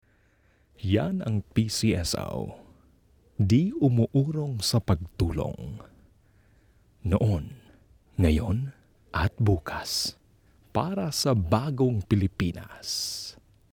Native Voice-Samples
Dokumentarfilme
Sennheiser Mikrofon